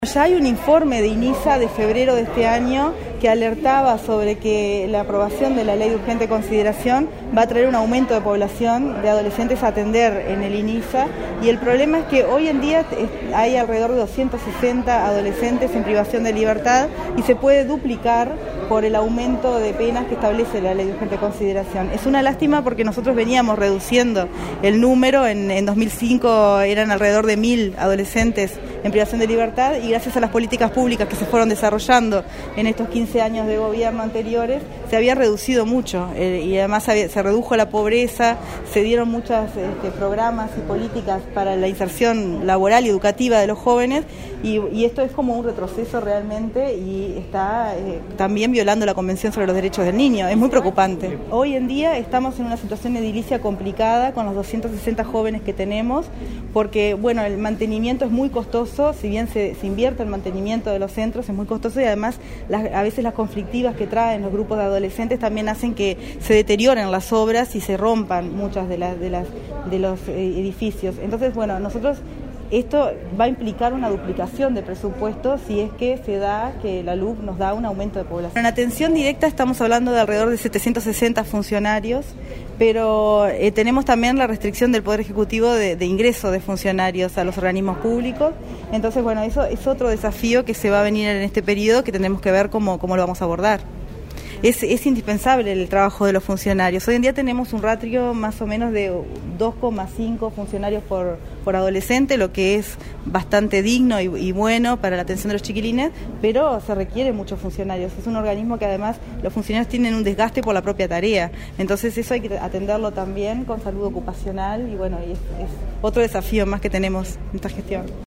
En rueda de prensa sostuvo que “un informe del Inisa de febrero alertaba que la aprobación de la Ley de Urgente Consideración (LUC) traería un aumento de la población de adolescentes a atender en el Inisa”.